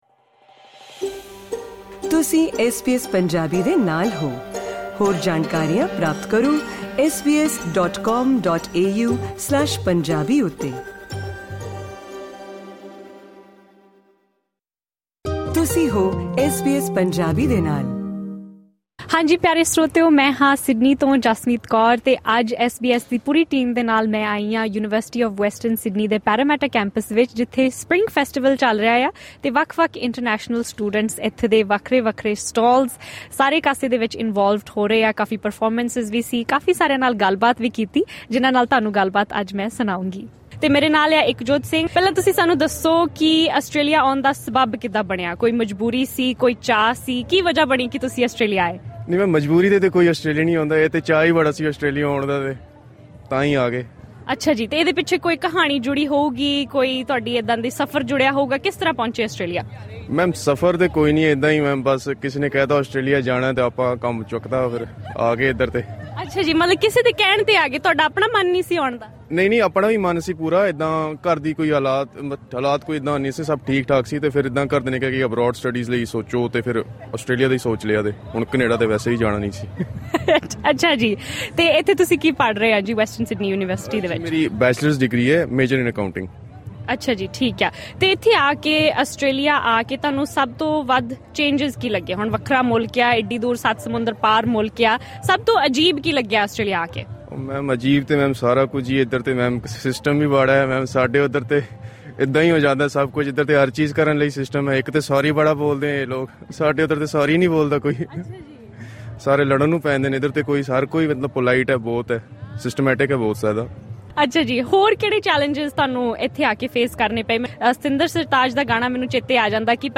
ਵੈਸਟਰਨ ਸਿਡਨੀ ਯੂਨੀਵਰਸਿਟੀ ਵਿੱਚ ਪੜ੍ਹ ਰਹੇ ਪੰਜਾਬੀ ਵਿਦਿਆਰਥੀਆਂ ਨੇ ਐਸ ਬੀ ਐਸ ਪੰਜਾਬੀ ਨਾਲ ਗੱਲਬਾਤ ਦੌਰਾਨ ਪਰਵਾਸ ਤੋਂ ਬਾਅਦ ਆਸਟ੍ਰੇਲੀਆ ਵਿੱਚ ਆਉਣ ਵਾਲੀਆਂ ਚੁਣੌਤੀਆਂ ਸਾਂਝੀਆਂ ਕੀਤੀਆਂ। ਉਨ੍ਹਾਂ ਦਾ ਕਹਿਣਾ ਹੈ ਕਿ ਇੱਥੇ ਰਹਿਣ ਦੀਆਂ ਮੁਸ਼ਕਿਲਾਂ ਨੇ ਵਿਦੇਸ਼ ਆਉਣ ਦੇ ਸੁਪਨੇ ਦੀ ਚਮਕ ਫਿੱਕੀ ਕਰ ਦਿੱਤੀ ਹੈ। ਭਾਰਤ ਵਿੱਚ ਪੜ੍ਹਦੇ ਹੋਏ ਵਿਦਿਆਰਥੀ ਆਖਰ ਵਿਦੇਸ਼ ਜਾਣ ਲਈ ਦਬਾਅ ਕਿਉਂ ਮਹਿਸੂਸ ਕਰਦੇ ਹਨ?